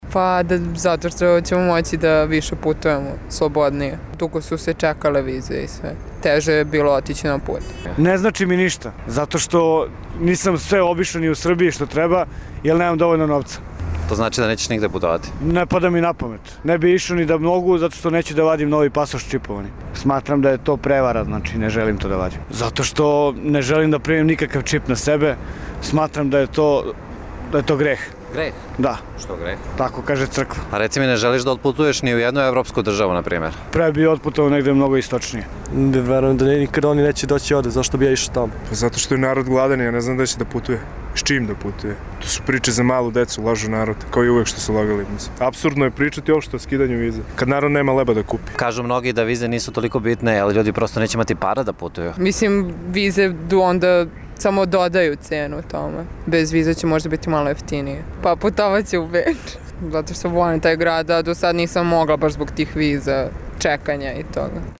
Anketa ukidanje viza - Beograd